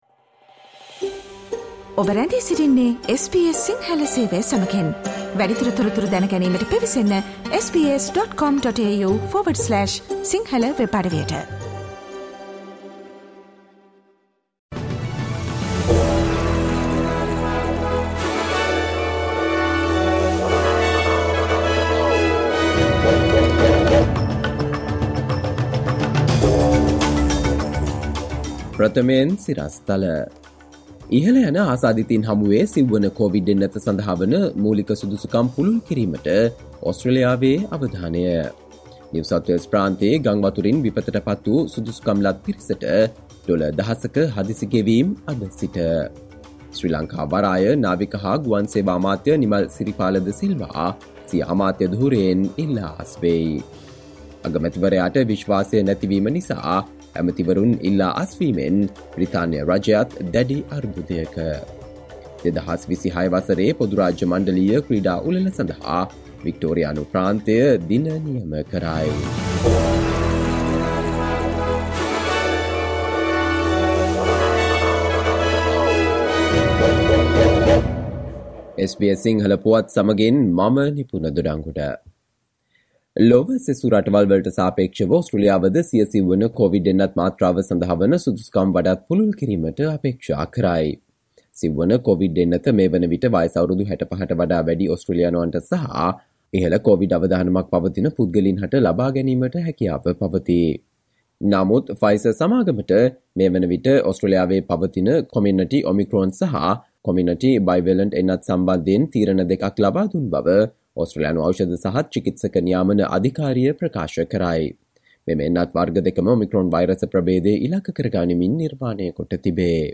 සවන්දෙන්න 2022 ජූලි 07 වන බ්‍රහස්පතින්දා SBS සිංහල ගුවන්විදුලියේ ප්‍රවෘත්ති ප්‍රකාශයට...